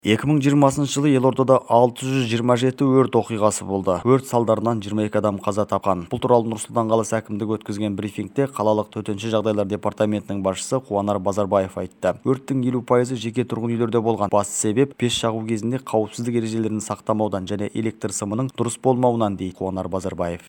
2020 жылы елордада өрт салдарынан 22 адам қаза тапты. Бұл туралы Нұр-Сұлтан қаласы әкімдігі өткізген брифингте қалалық Төтенше жағдайлар департаментінің басшысы Қуанар Базарбаев айтты.